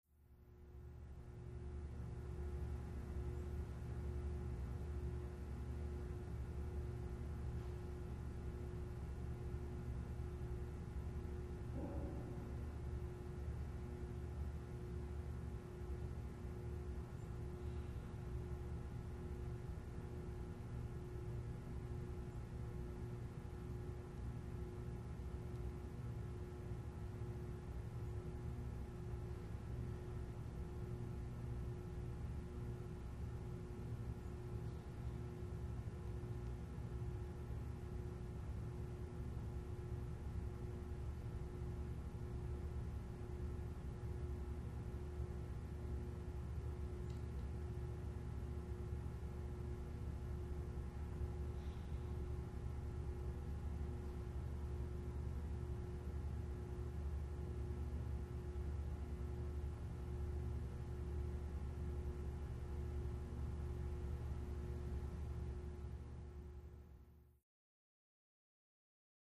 Parking Garage Interior, W Electrical Buzz And Low End Rumble.